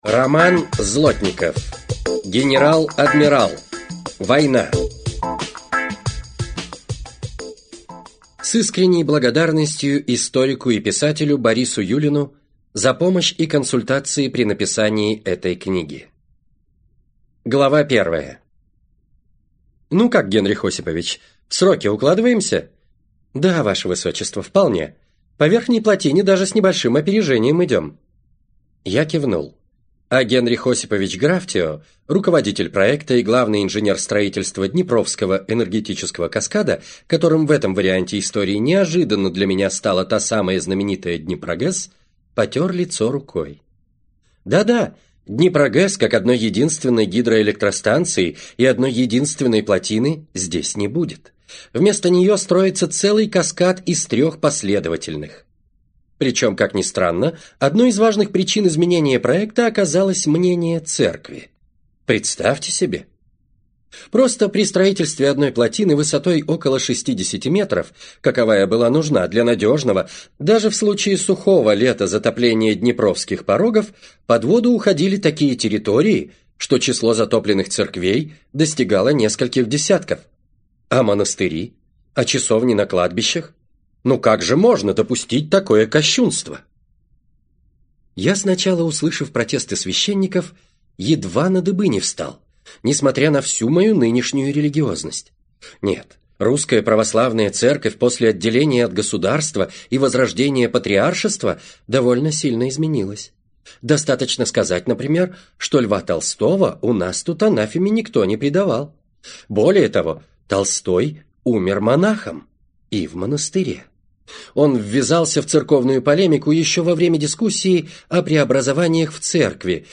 Аудиокнига Война - купить, скачать и слушать онлайн | КнигоПоиск